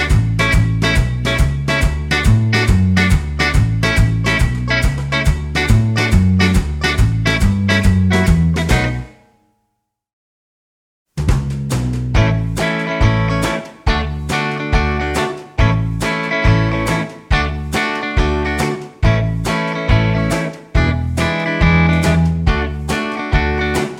With Harmony Pop (1960s) 3:06 Buy £1.50